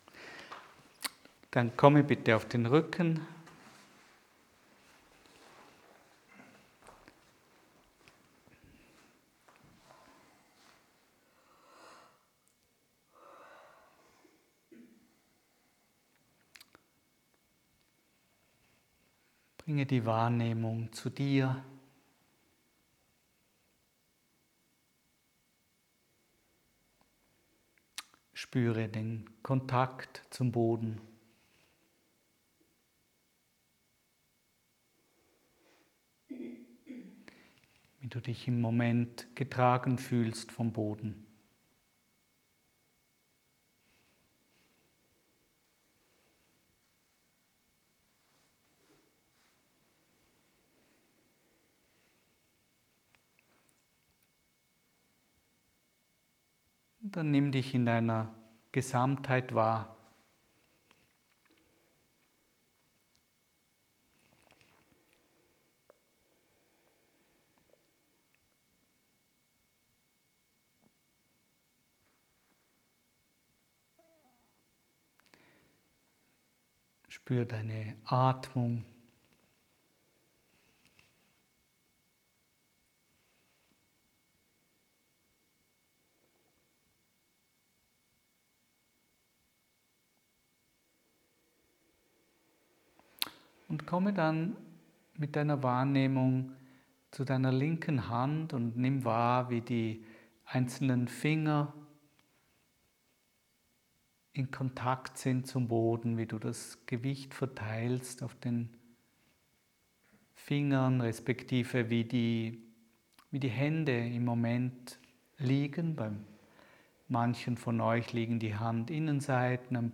Quelle: Ankle Movements AY 251 Die Lektion des Monats ist ein Live-Mitschnitt meiner Gruppenkurse in Feldenkrais ® Bewusstheit durch Bewegung.